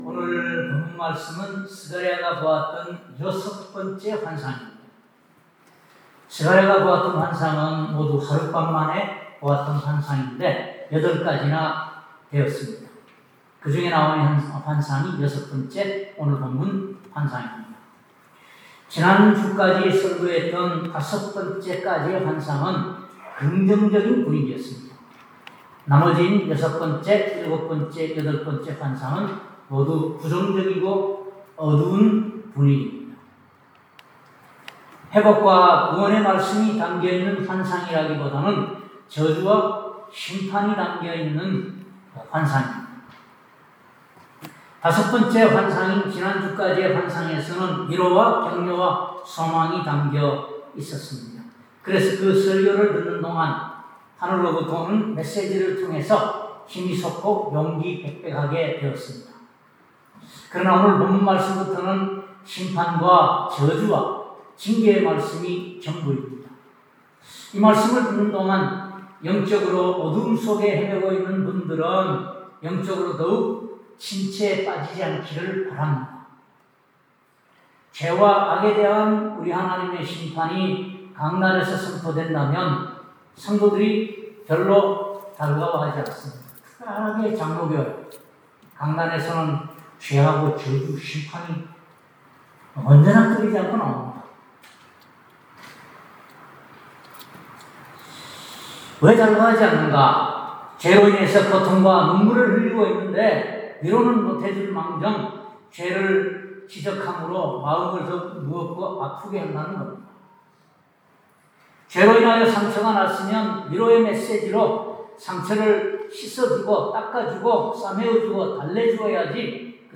슥 5:1-4 Service Type: 주일예배 오늘 본문 말씀은 스가랴가 보았던 여섯 번째의 환상입니다.